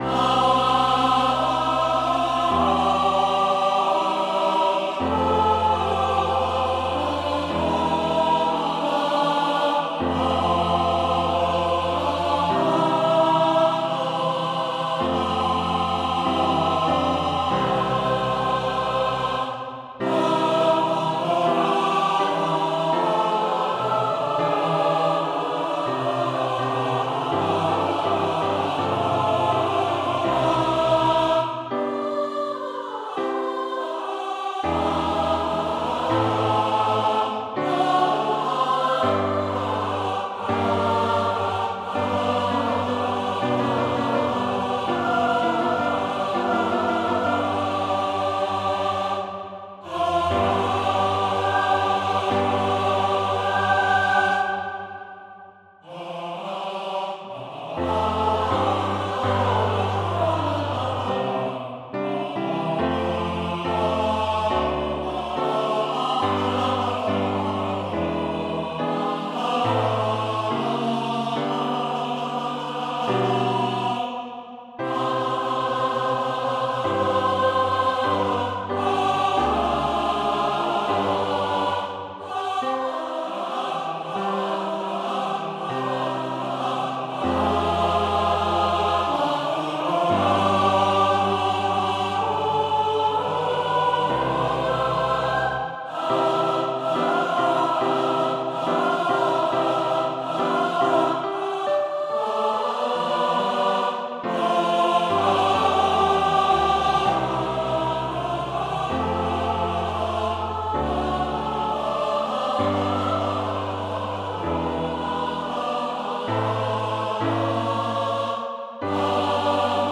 Touch-GardenSong-SATB-Piano-250917.pdf
MIDI rendering of score: